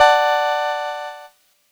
Cheese Chord 08-D3.wav